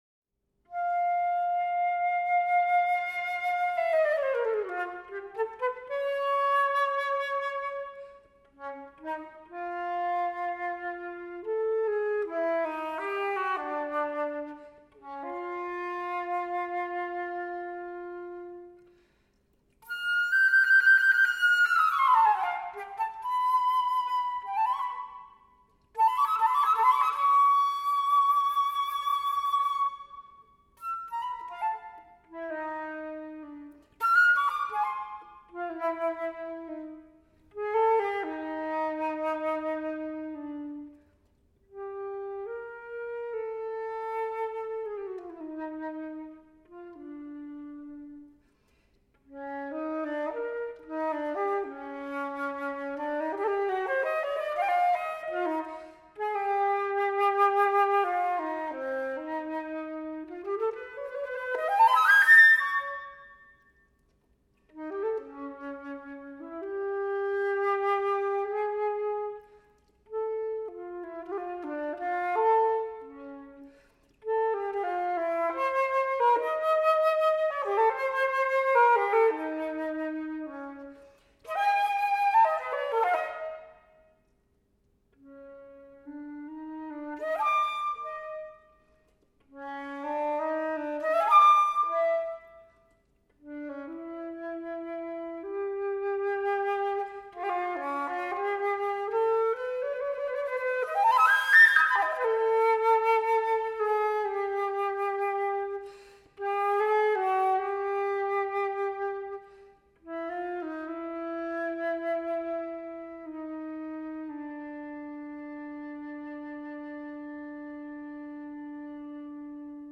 For Flute.